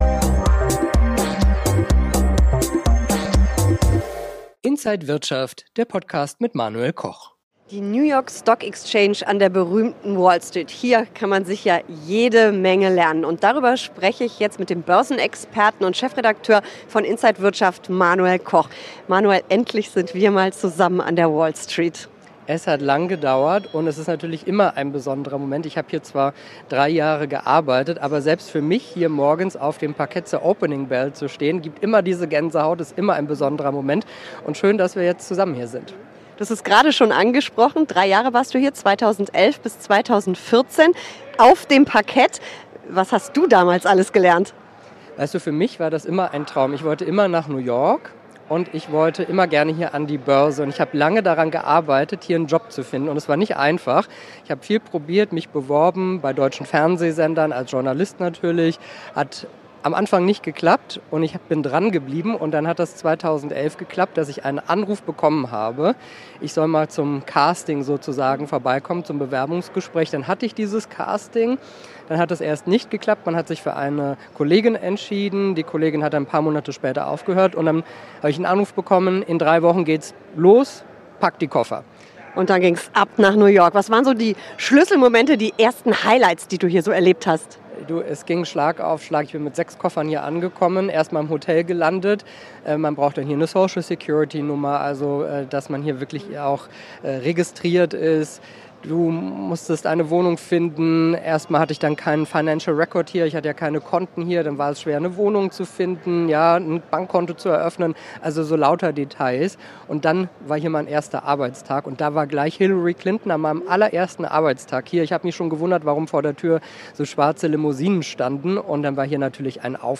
Alle Details im Interview von Finanzjournalistin